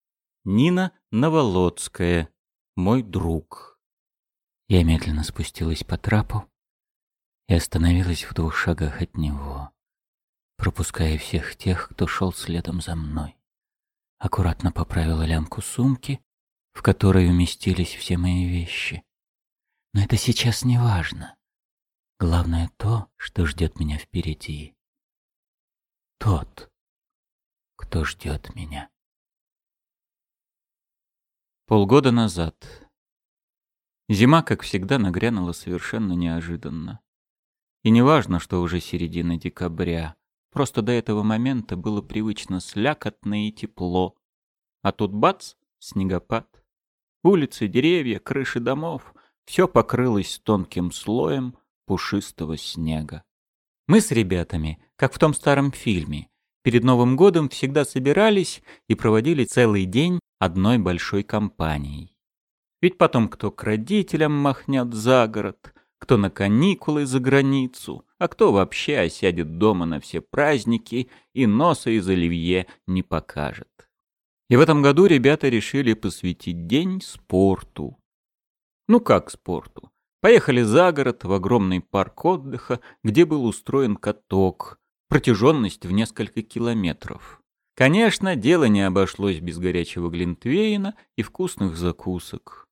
Аудиокнига Мой друг | Библиотека аудиокниг
Aудиокнига Мой друг Автор Нина Новолодская Читает аудиокнигу Евгений Лебедев.